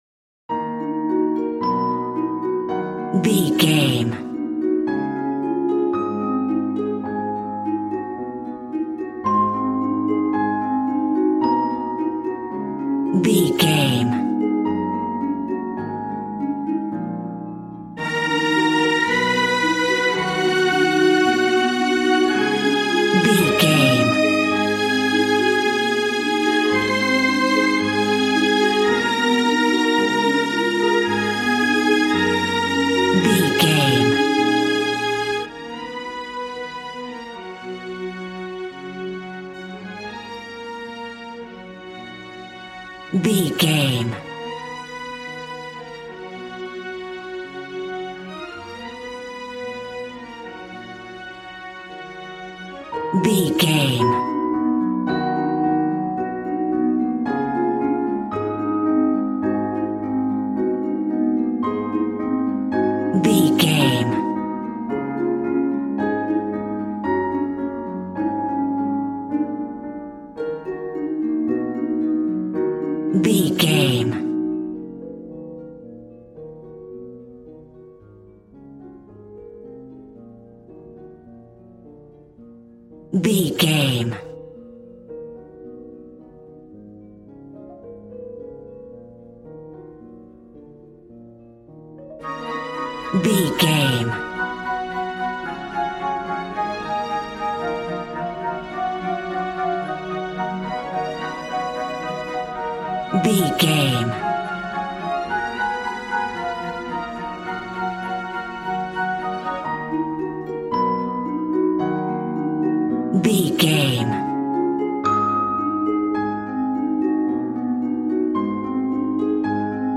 Regal and romantic, a classy piece of classical music.
Aeolian/Minor
E♭
regal
strings
violin